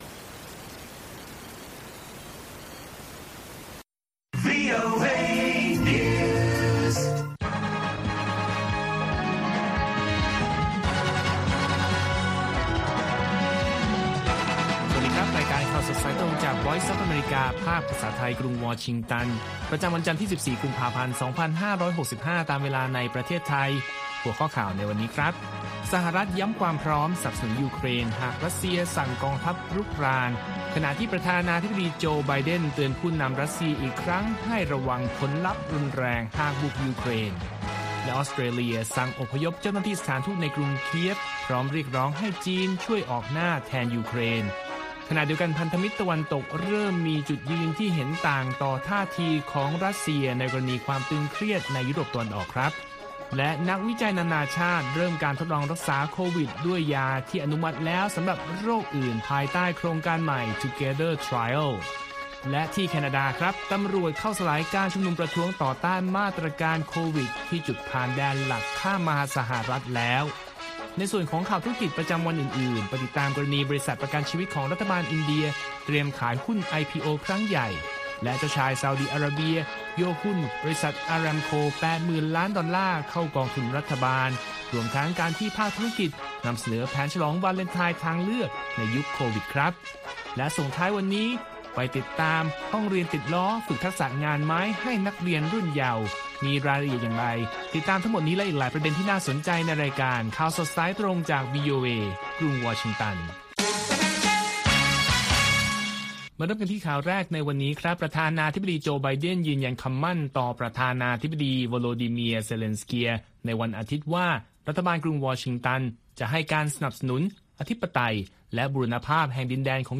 ข่าวสดสายตรงจากวีโอเอ ภาคภาษาไทย 6:30 – 7:00 น. ประจำวันที่ 14 กุมภาพันธ์ 2565 ตามเวลาในประเทศไทย